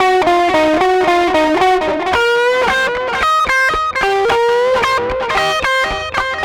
Track 15 - Guitar 06.wav